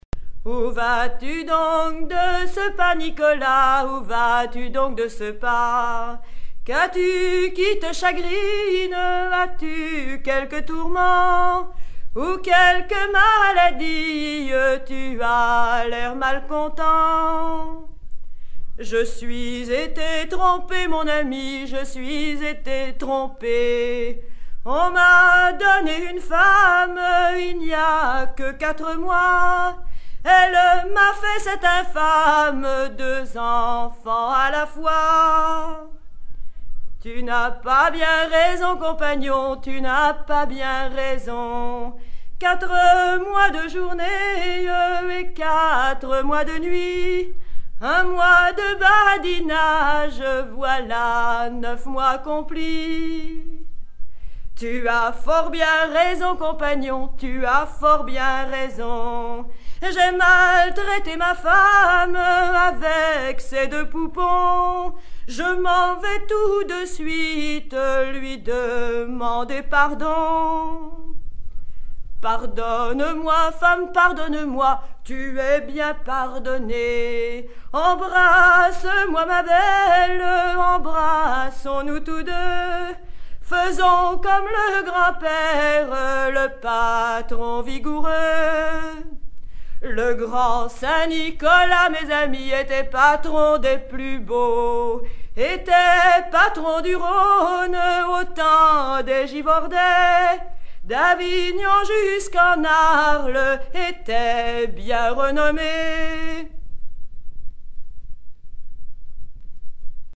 Chants de mariniers
Pièce musicale éditée